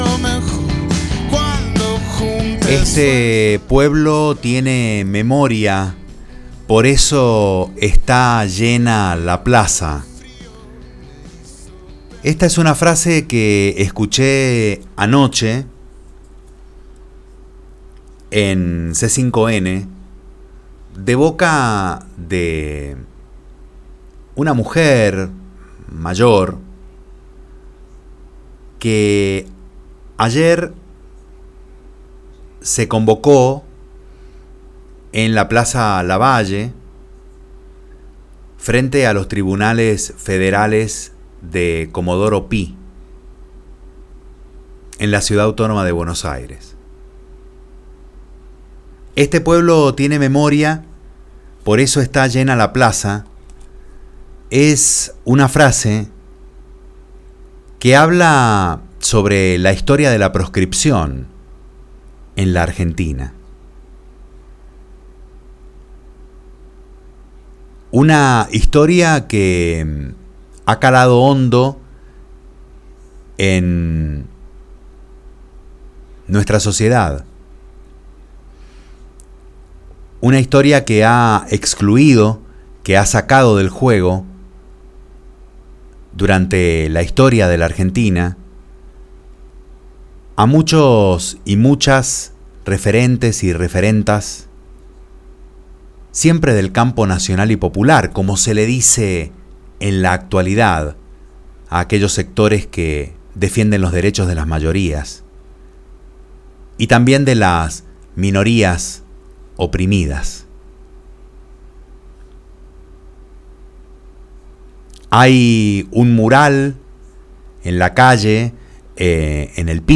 comentario editorial